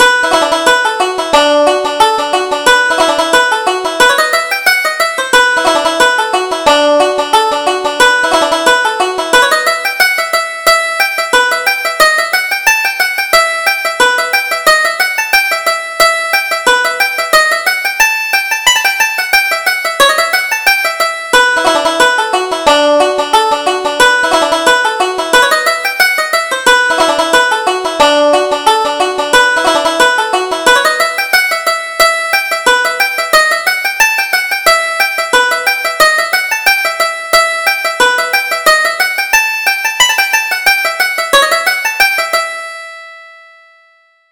Reel: Pat Tuohy's Reel